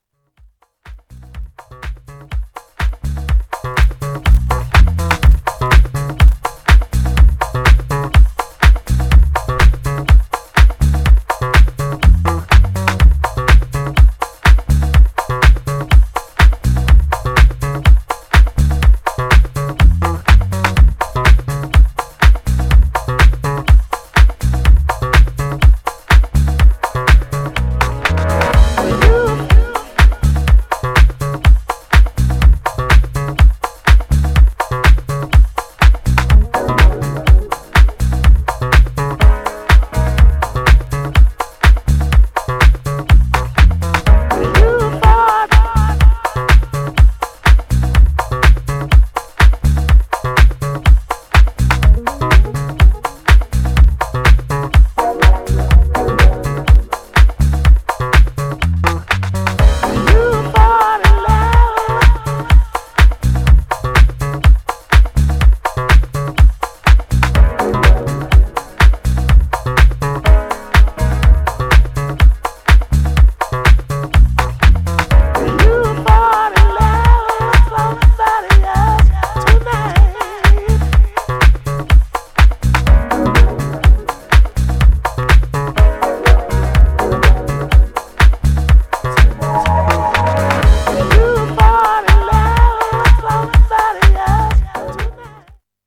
Styl: Disco, House